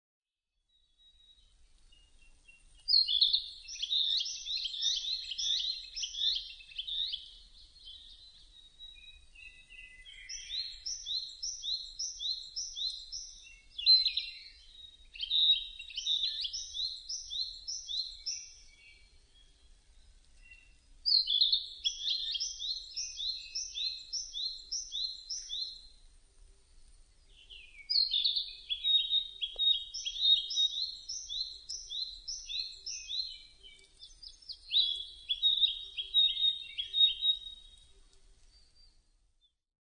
キビタキ　Ficedula narcissinaヒタキ科
日光市稲荷川中流　alt=730m  HiFi --------------
Mic.: Sound Professionals SP-TFB-2  Binaural Souce
他の自然音：　 ウグイス・クロツグミ・シジュウカラ・ツツドリ